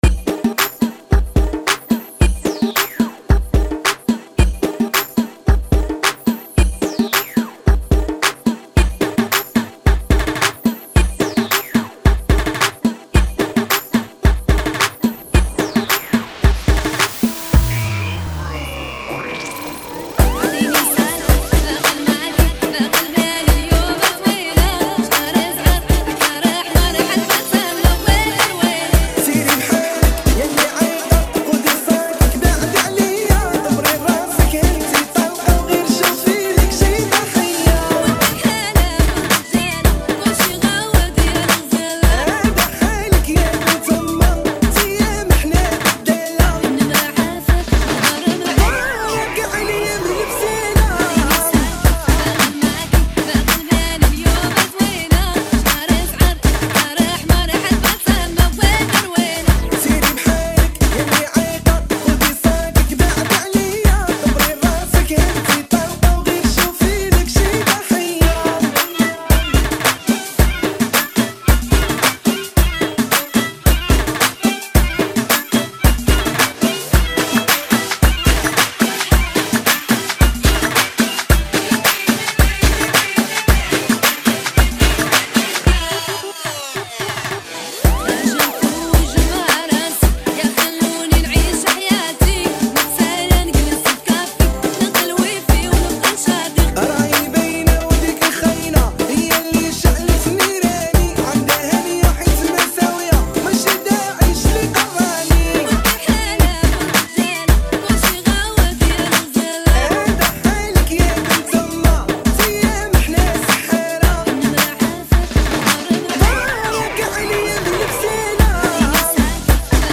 [ 110 bpm ] FunKy
دويتو